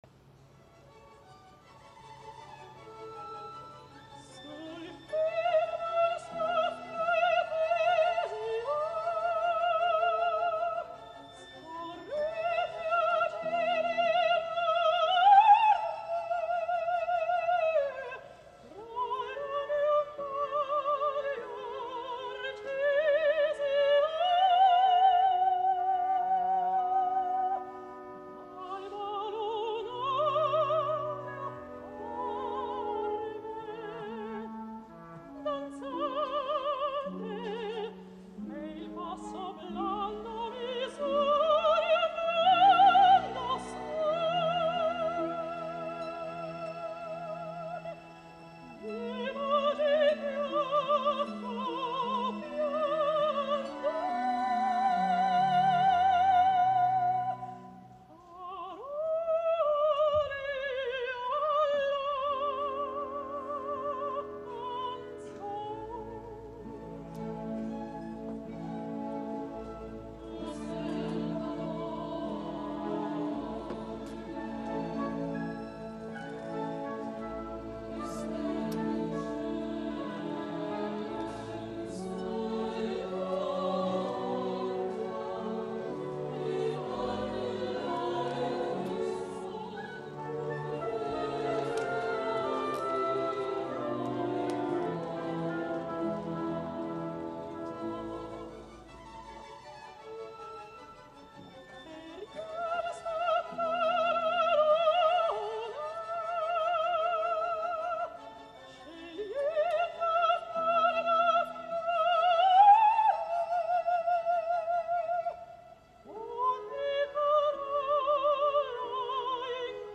FALSTAFF A LA SCALA 2013 | IN FERNEM LAND